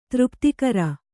♪ třptikara